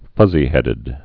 (fŭzē-hĕdĭd)